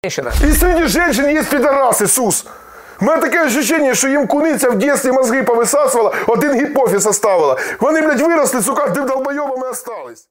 • Качество: 192, Stereo
голосовые